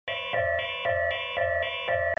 دانلود آهنگ هشدار 2 از افکت صوتی اشیاء
جلوه های صوتی
دانلود صدای هشدار 2 از ساعد نیوز با لینک مستقیم و کیفیت بالا